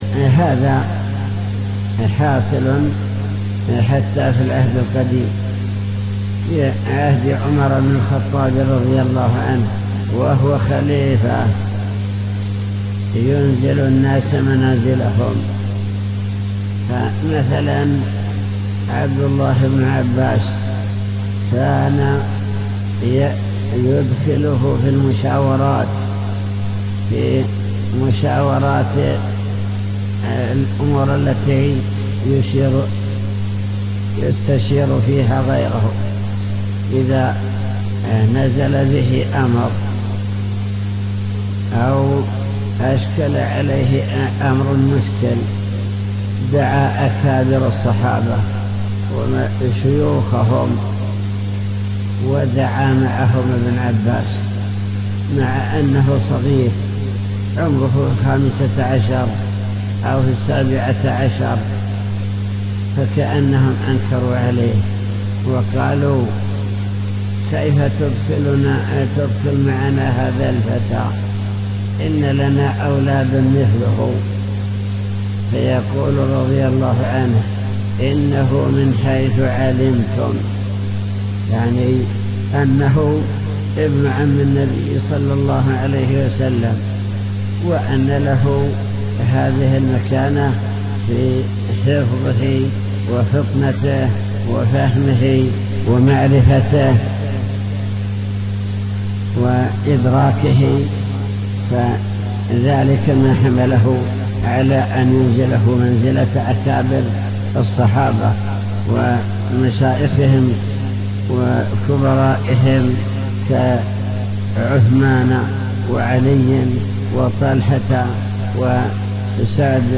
المكتبة الصوتية  تسجيلات - كتب  شرح كتاب بهجة قلوب الأبرار لابن السعدي شرح حديث اشفعوا فلتؤجروا